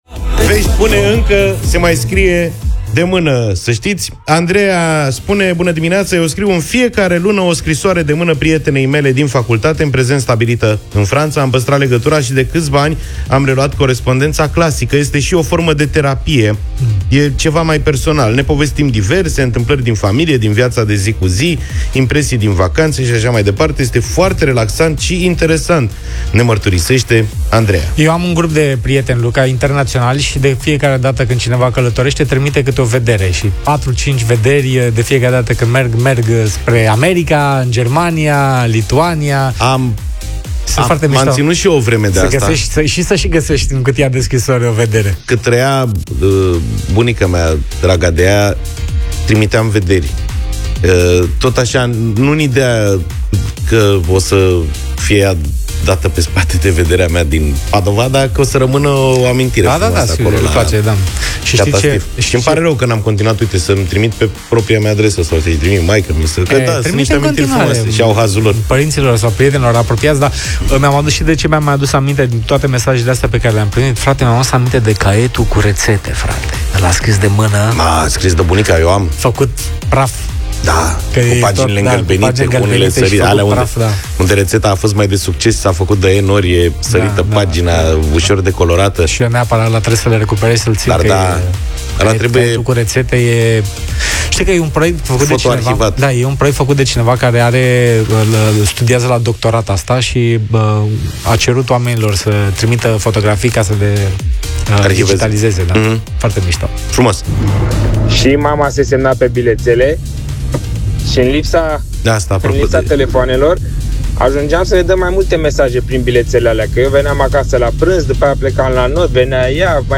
i-au întrebat pe ascultători: Când ați mai pus mâna pe pix pentru ceva serios?